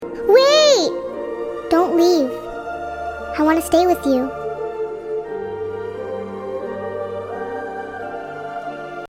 puff puff wants to stay with you Meme Sound Effect